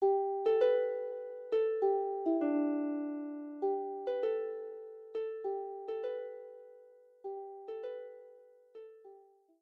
lever or pedal harp